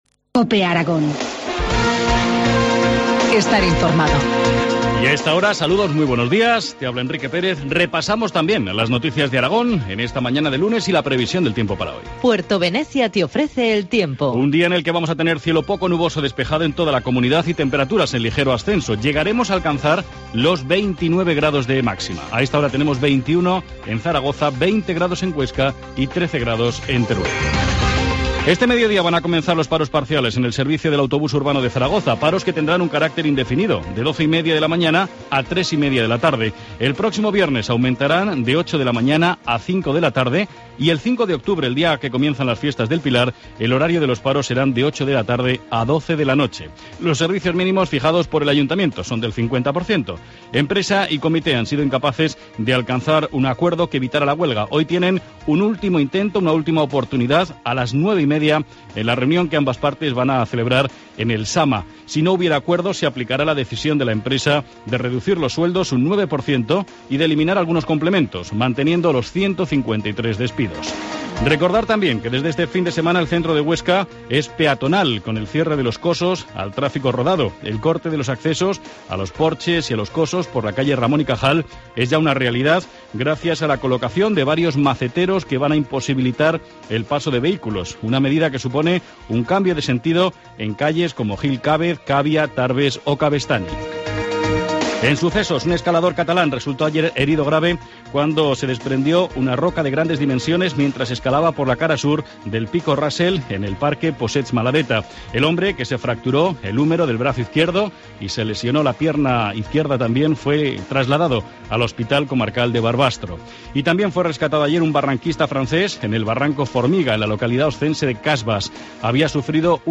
Informativo matinal, lunes 23 de septiembre, 8.25 horas